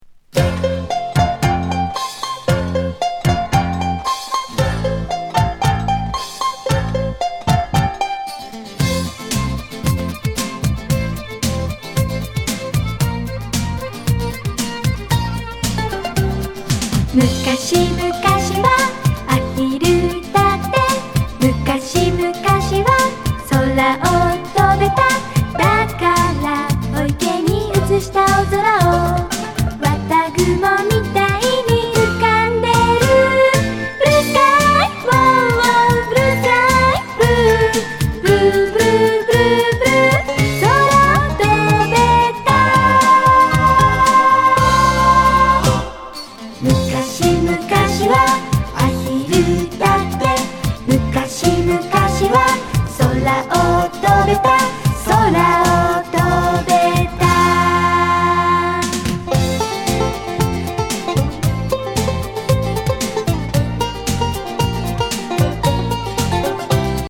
チープ電子ドラムのキッチュ・エレ・ポップ